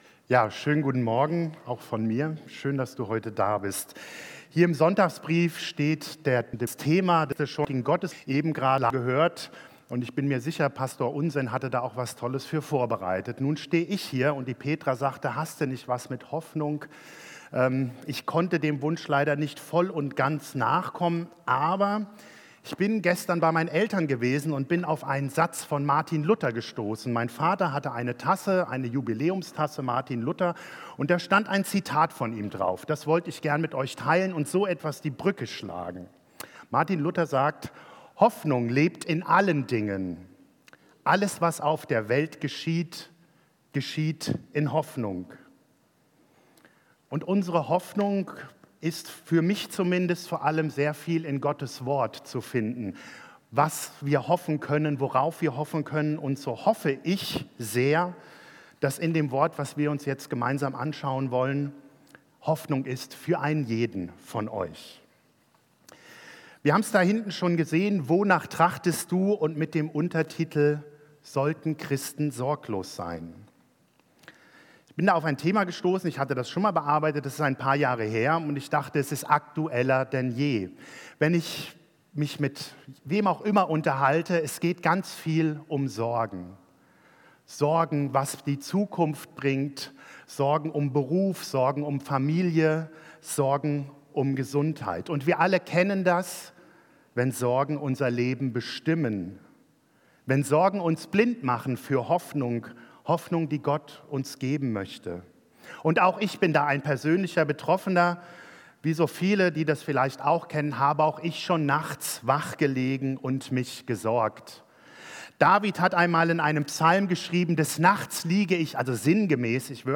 Predigt Wonach trachtest du?